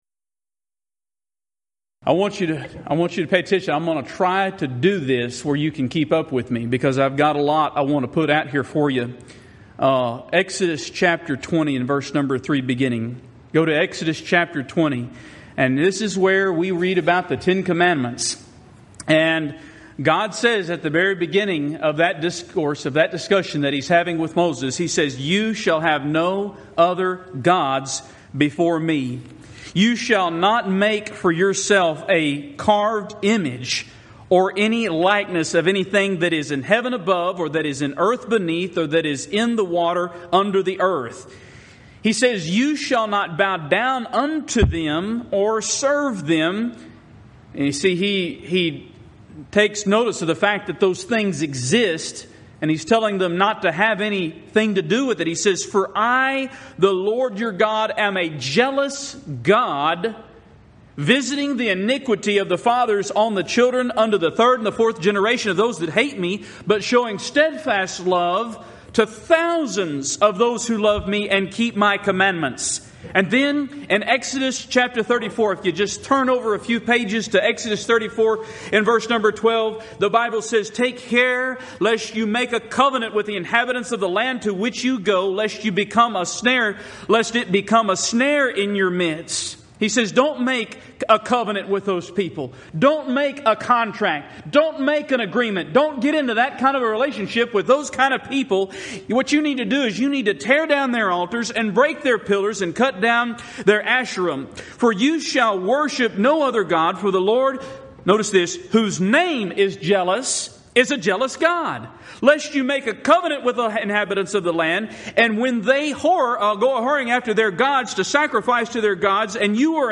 Event: 2015 South Texas Lectures
lecture